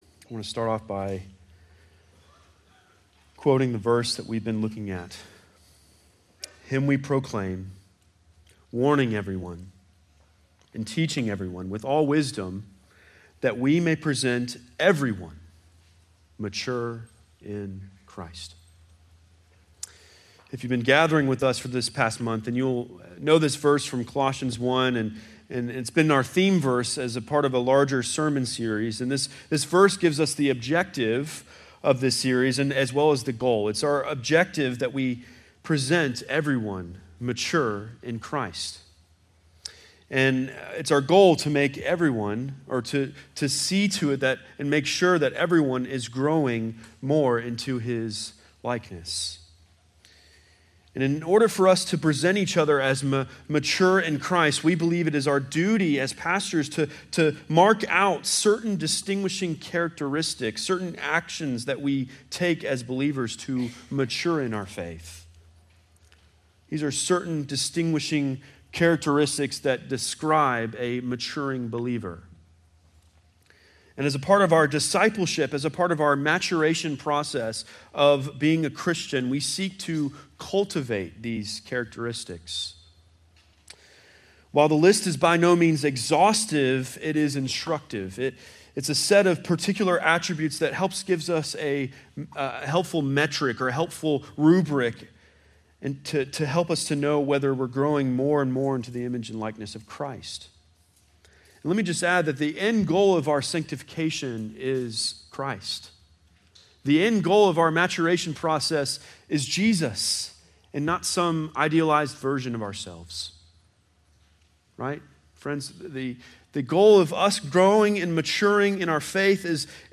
2024 Mature Disciples Matthew 28:18-20 The next sermon in our series on growing into Christ like maturity focusing on the call on each of us as Christians to make disciples for the glory of God.